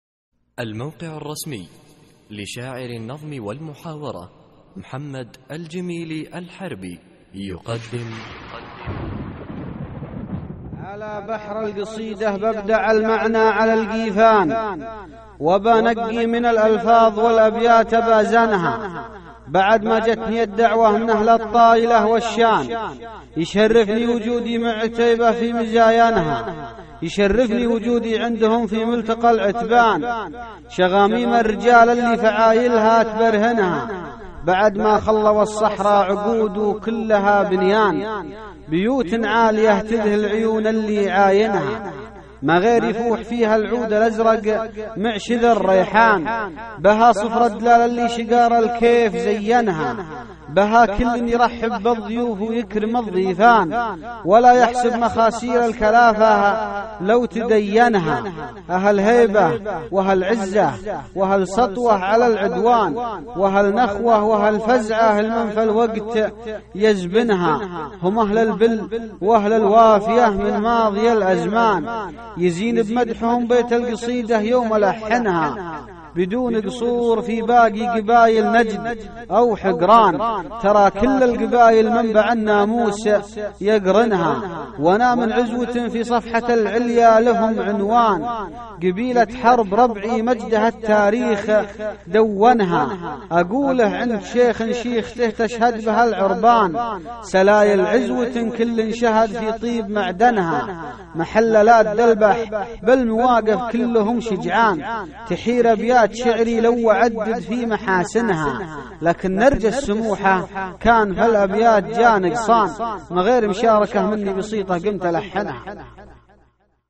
القصـائــد الصوتية
اسم القصيدة : ملتقى العتبان ~ إلقاء